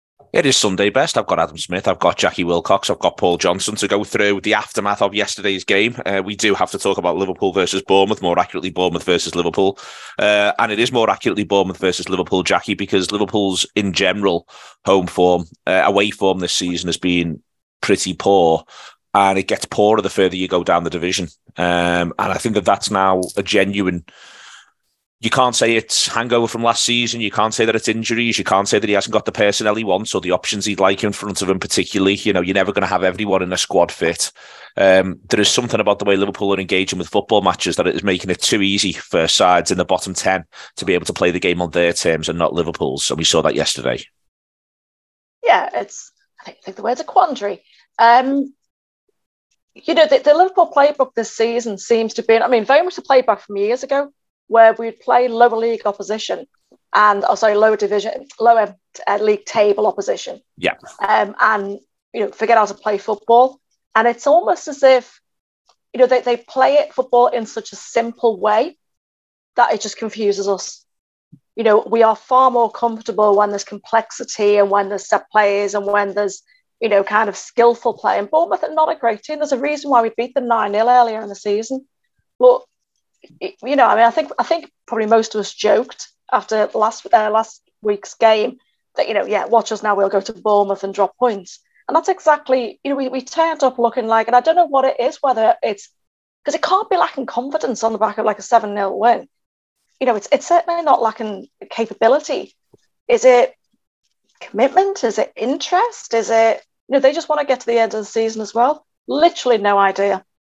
Below is a clip from the show – subscribe for more of the best from Bournemouth 1 Liverpool 0…